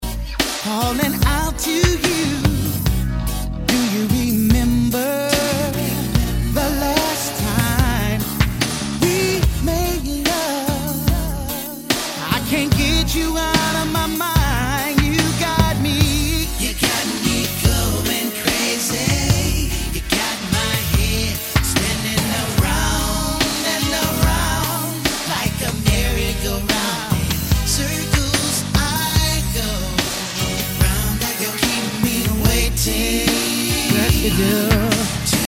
r&b/g-funk